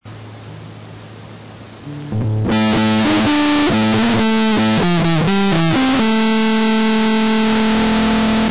Я играю пару нот на гитаре а вы отгадываете
звук ужс просто